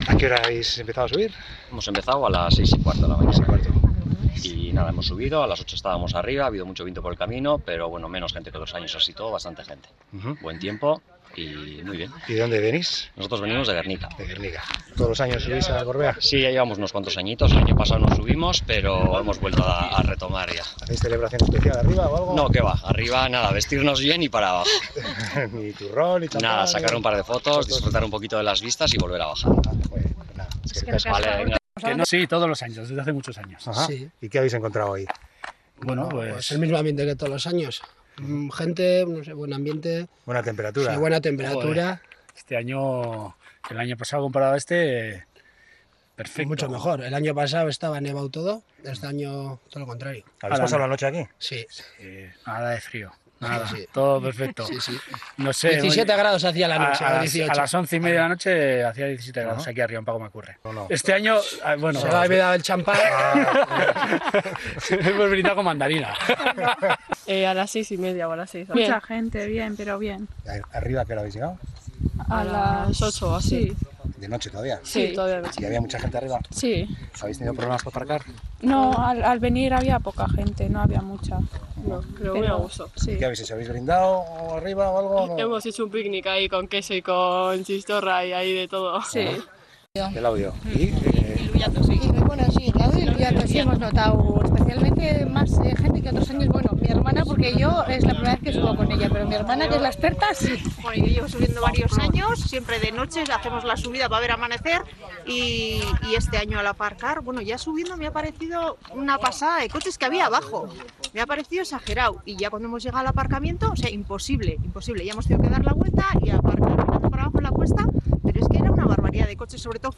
Hoy en Onda Vasca hemos hablando con algunas de las personas que han decidido seguir la tradición y despedir el 2021 subiendo a la cima del monte.